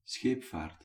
Dutch Belgian Standard[55] scheepvaart
[ˈsxeːˌp̪͡faˑrt] 'navigation' Laminal.[55] See Dutch phonology
Nl-scheepvaart_(Belgium).ogg.mp3